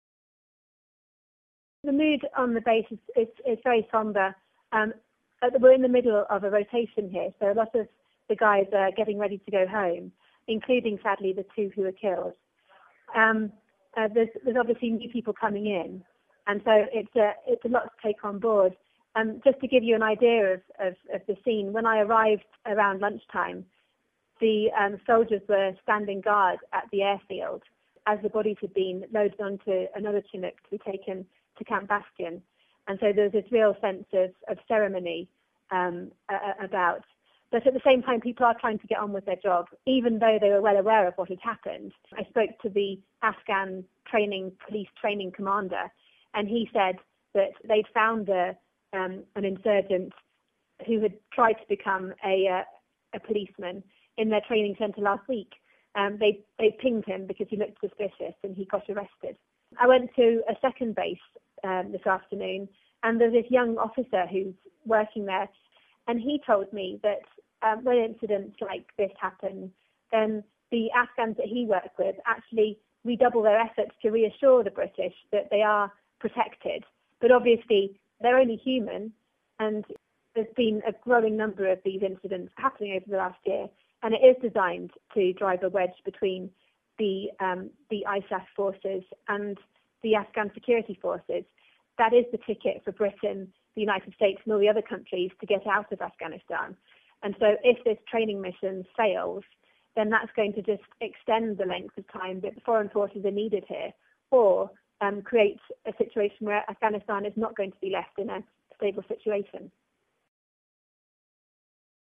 reports from Lashkar Gah, Afghanistan where two British troops have been shot dead by an Afghan solider